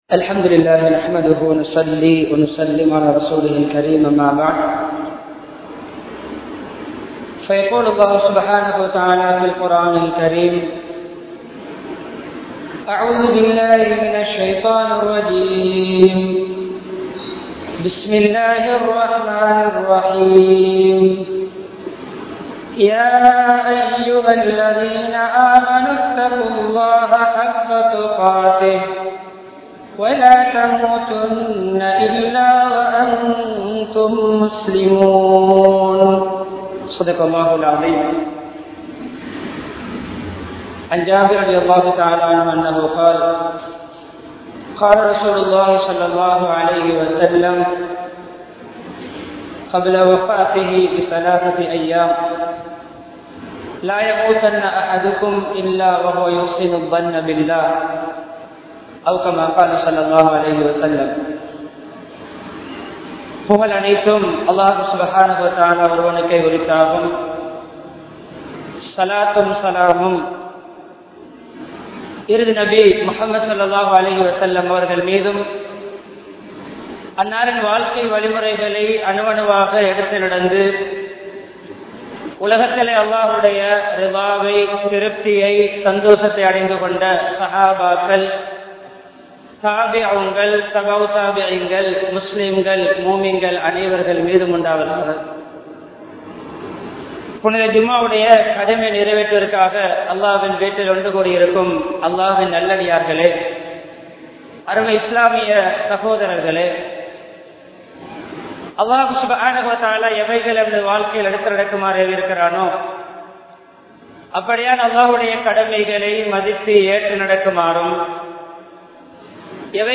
Ungalin Iruthi Mudivu Evvaaru Amaiya Veandum? (உங்களின் இறுதி முடிவு எவ்வாறு அமைய வேண்டும்?) | Audio Bayans | All Ceylon Muslim Youth Community | Addalaichenai
Salihath Jumua Masjidh